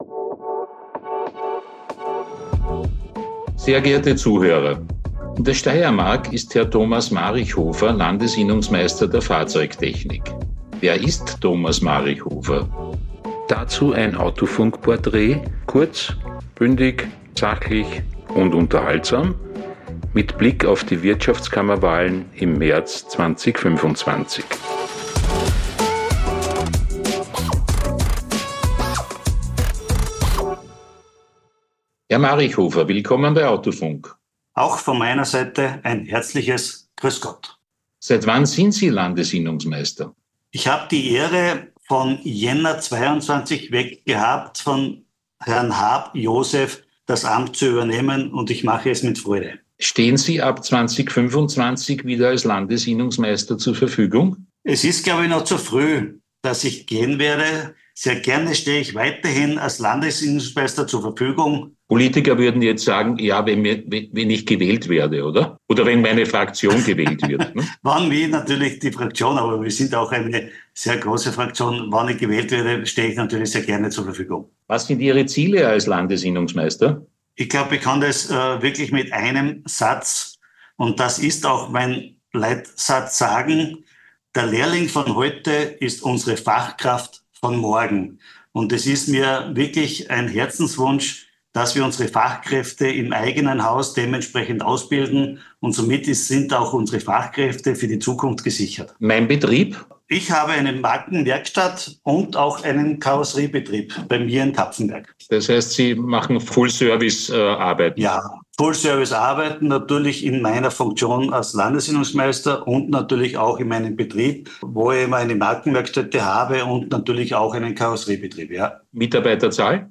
Gespräch.